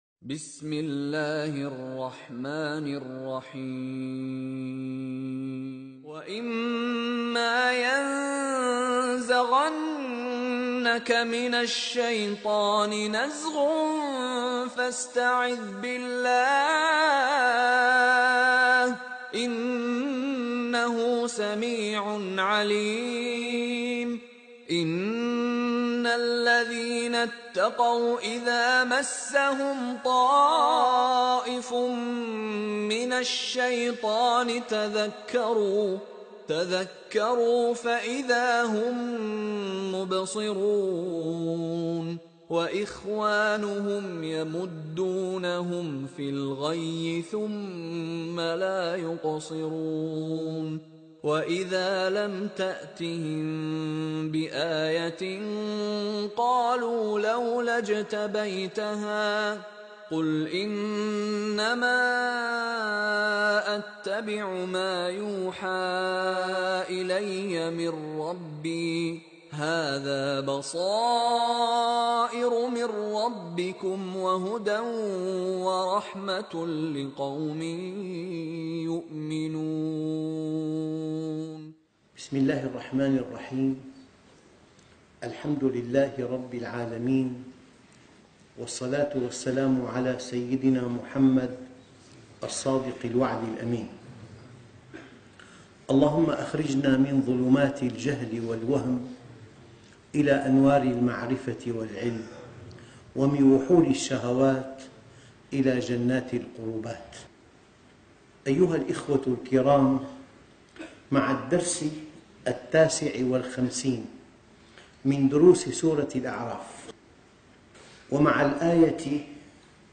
الدرس (59) تفسير سورة الأعراف - الشيخ محمد راتب النابلسي